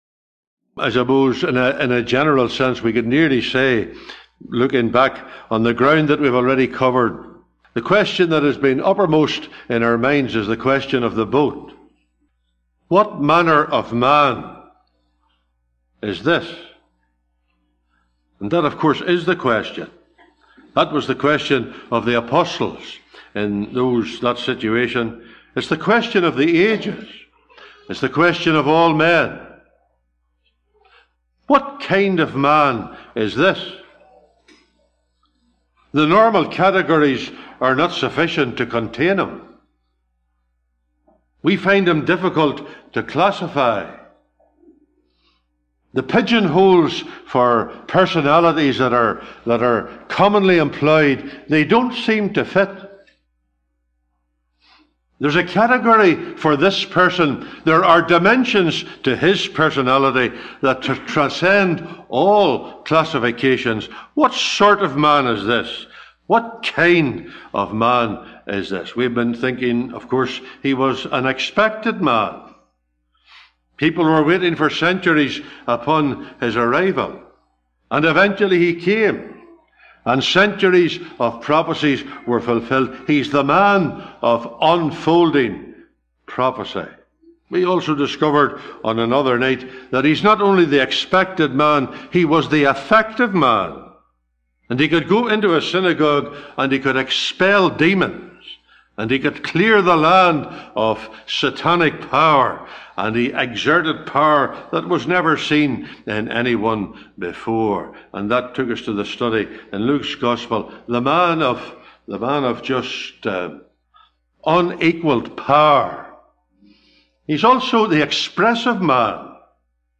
Readings: Acts 17:30-31, Rom 5:12-17, 19-21, 1 Cor 15:45-49, 1 Tim 2:5-7, 13-14. (Recorded in Stark Road Gospel Hall, 12th Sept 2024)